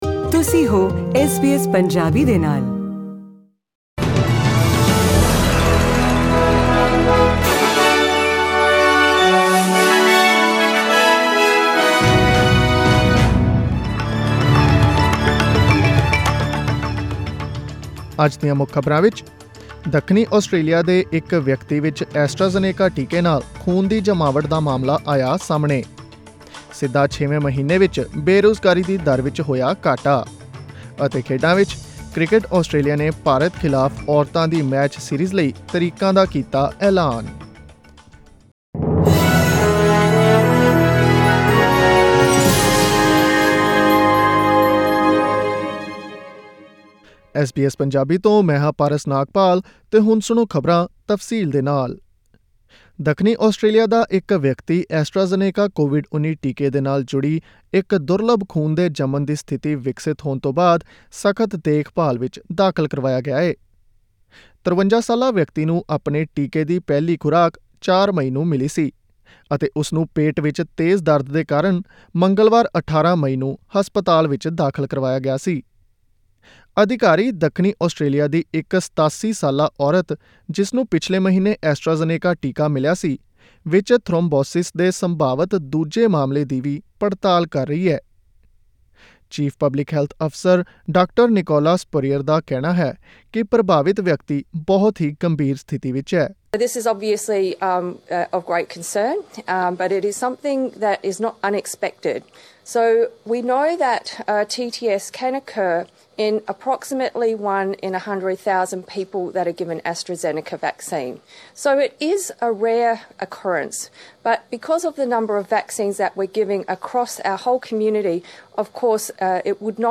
Click on the audio icon in the picture above to listen to the news bulletin in Punjabi.